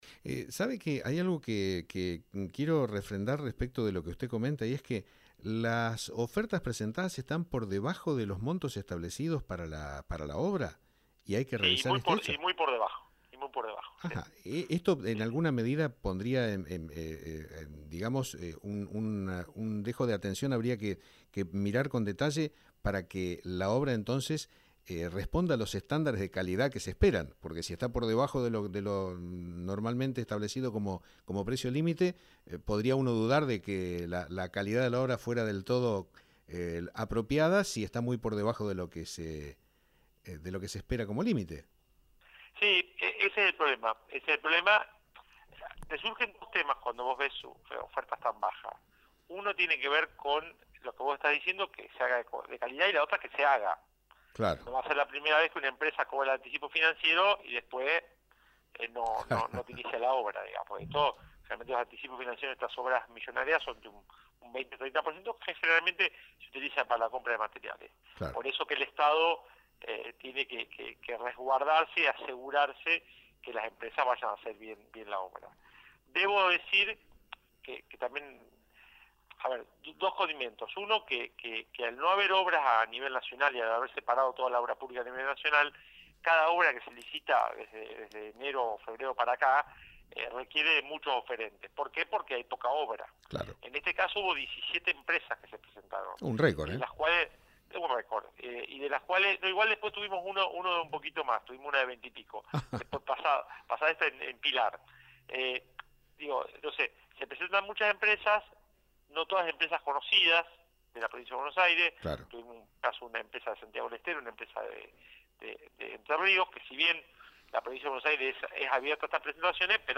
El Subsecretario de Recursos Hídricos de la Provincia de Buenos Aires Nestor Alvarez nos comentó acerca de las gestiones y las Obras Pluviales que se realizarán en el Partido de Villarino.